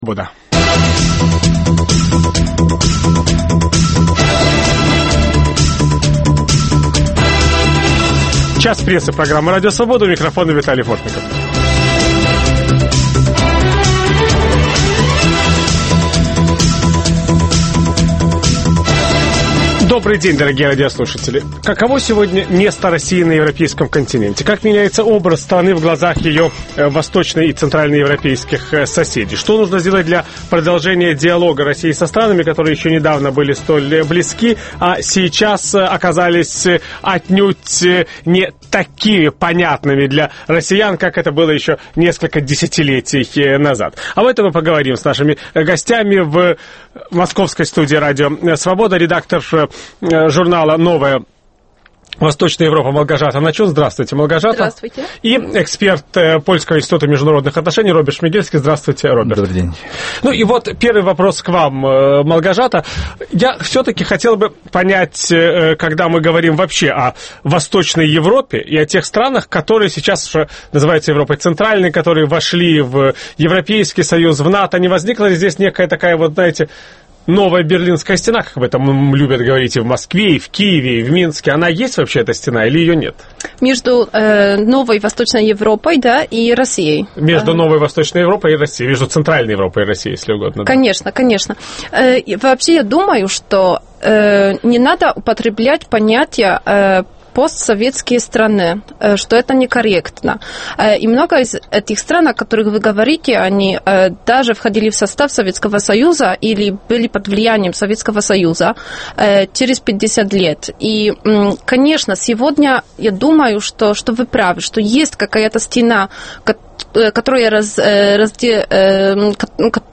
Место России в Центральной Европе: Виталий Портников беседует с польскими журналистами.